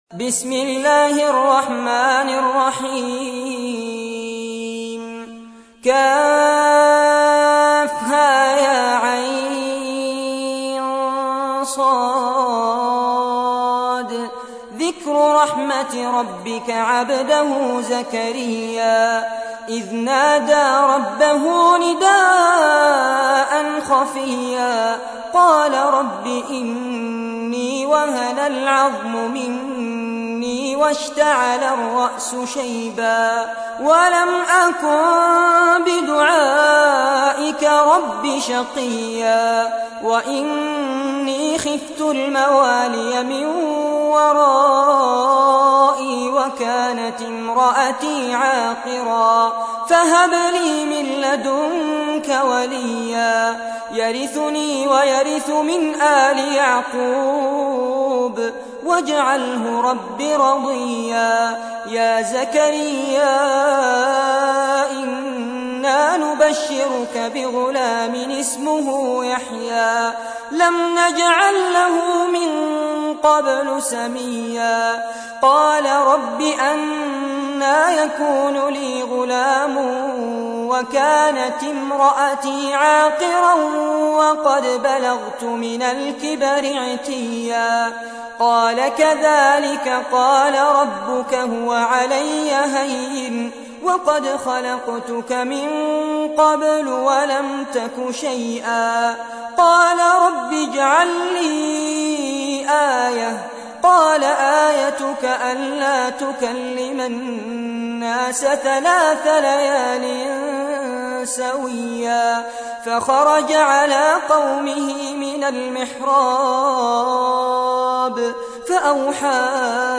تحميل : 19. سورة مريم / القارئ فارس عباد / القرآن الكريم / موقع يا حسين